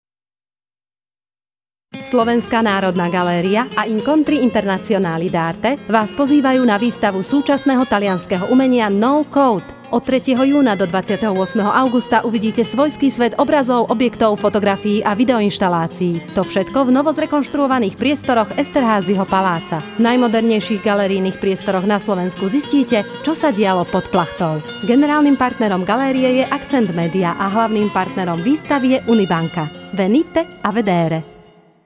rádio spot (mp3)